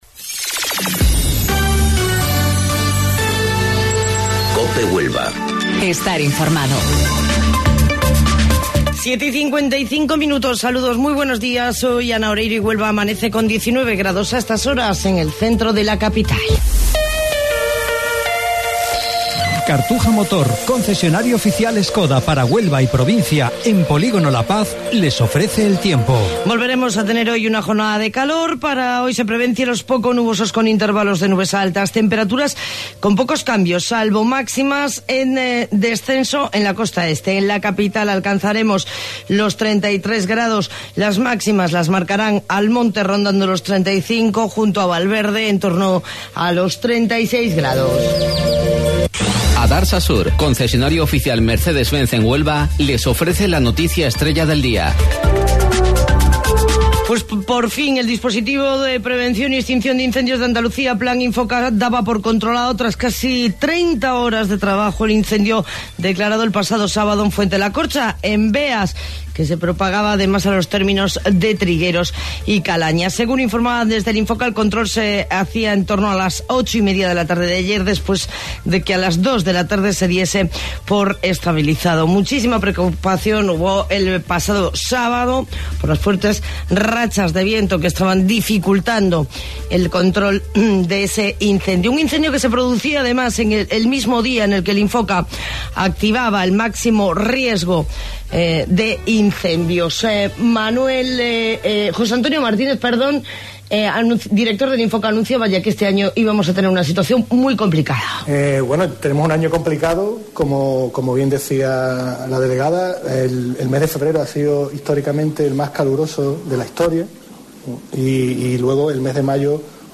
AUDIO: Informativo Local 07:55 del 3 de Junio